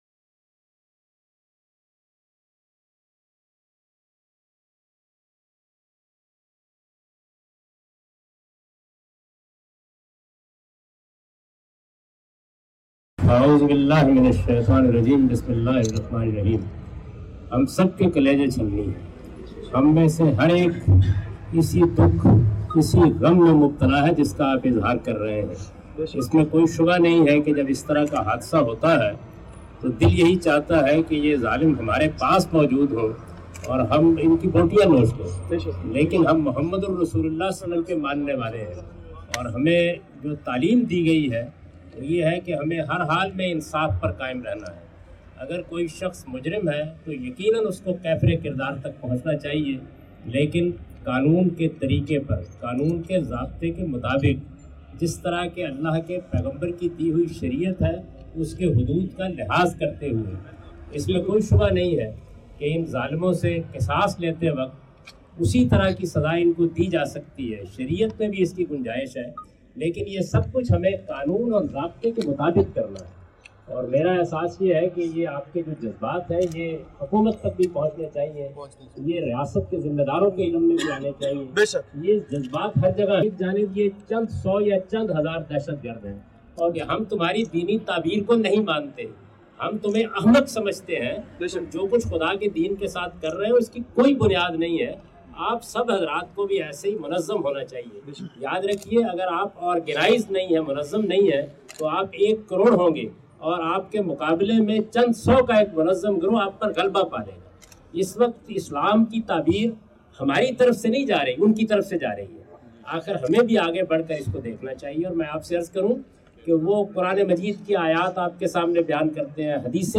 Prayers for Children of Peshawar Massacre and A short talk by Javed Ahmed Ghamidi on the issue of horrific Peshawar Tragedy in which he analyzed the argument forwarded by Taliban regarding Hadith of Banu Qurayza.